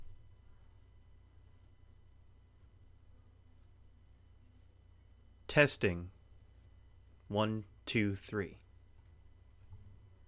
Blue Yeti only makes feedback noise when recording?
However, when I open audacity and press record, I get this weird feedback noise…I’ve attached it below.
It’s pretty loud during silence, and you can even hear it when I’m speaking.
The recording is not loud enough.
Even in your test, the noise is not severe.